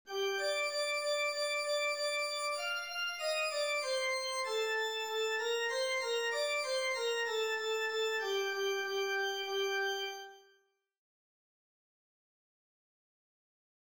Example 7 would pass for a Hungarian psalmodic tune, were its main cadence b3.
Example 7. Four-sectioned Tajik song with cadences on the 2nd degree